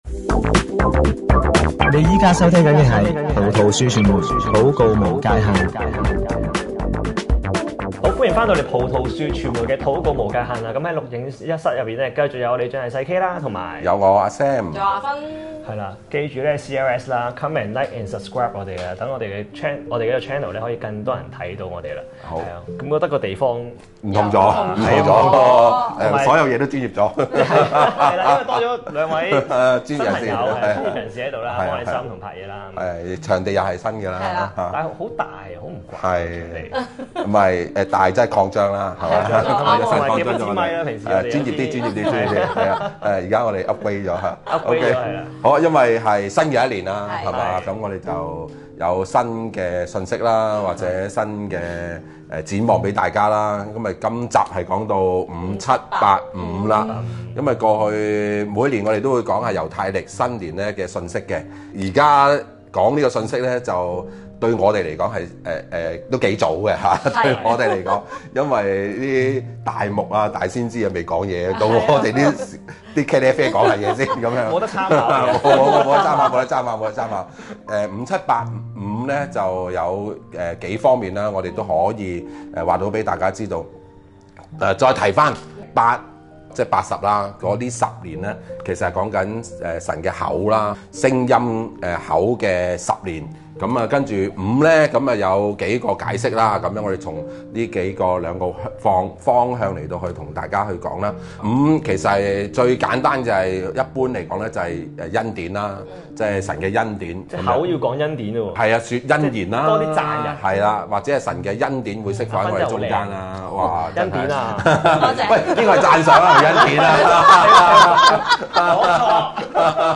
◎節目內播放歌曲◎